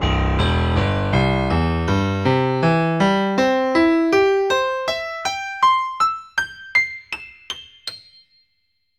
In Bild 8.48 siehst du das zeitliche Frequenzspektrum hintereinander gespielter Klänge als Ergebnis einer Fourier-Analyse.
Repeated-c-major-triad.ogg